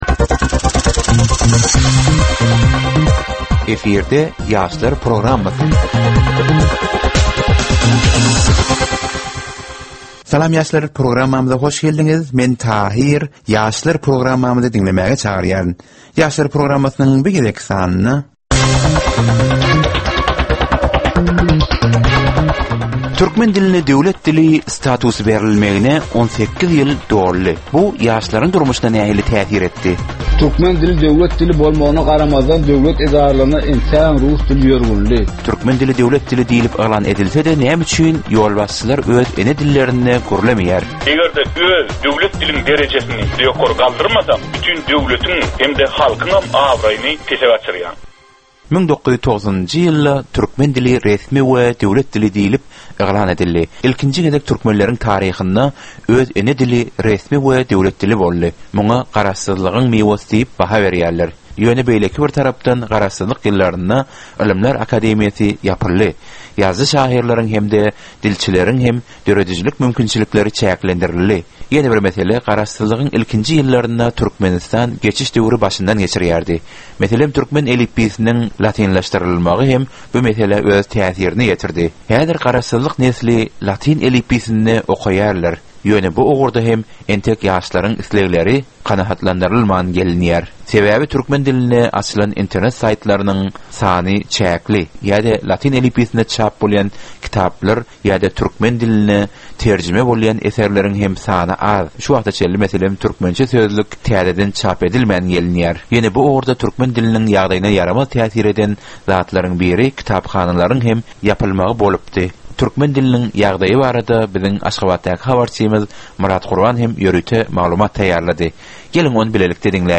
Bu gepleşikde ýaşlaryň durmuşyna degişli dürli täzelikler we derwaýys meseleler barada maglumatlar, synlar, bu meseleler boýunça adaty ýaslaryň, synçylaryň we bilermenleriň pikrileri, teklipleri we diskussiýalary berilýär. Gepleşigiň dowamynda aýdym-sazlar hem eşitdirilýär.